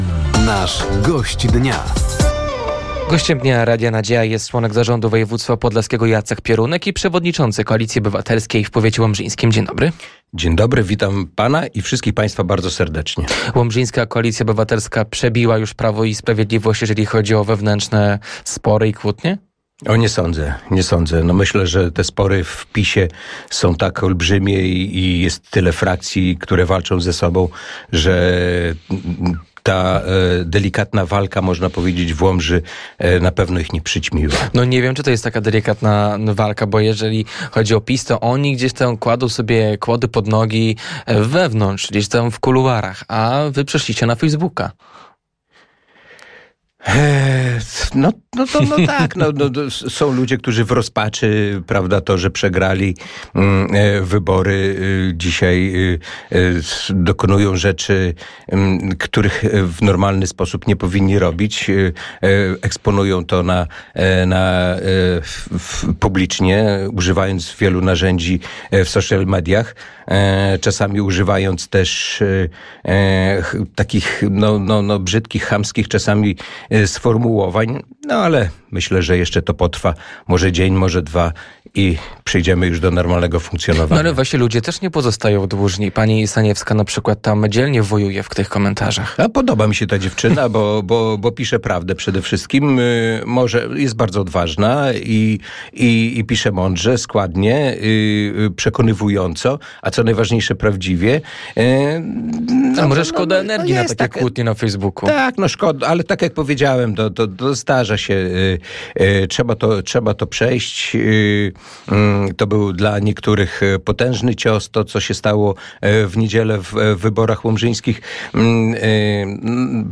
Gościem Dnia Radia Nadzieja był członek zarządu województwa podlaskiego Jacek Piorunek. Tematem rozmowy była między innymi sytuacja wewnątrz łomżyńskiej Koalicji Obywatelskiej, inwestycje w skansenie kurpiowskim w Nowogrodzie, baza PKS w Łomży oraz konkurs Podlaska Marka Roku.